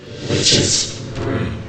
get_witch_broom.ogg